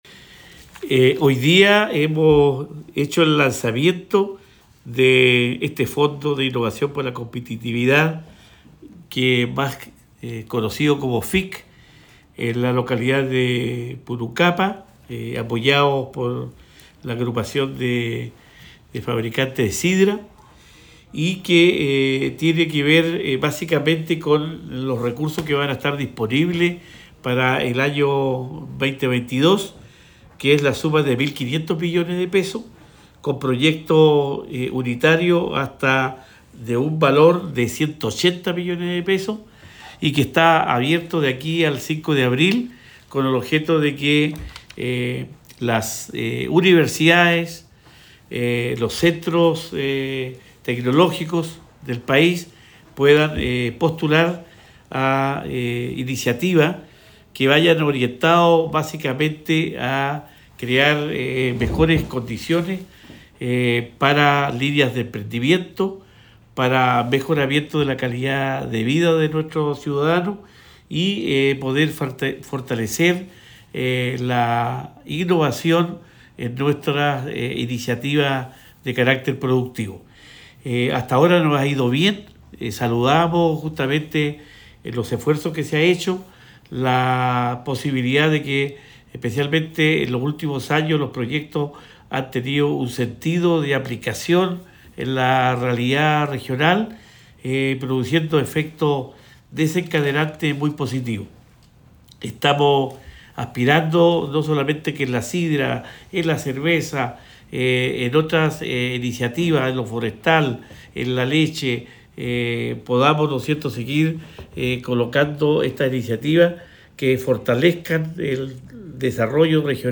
Cuña_Gobernador_FIC2022.mp3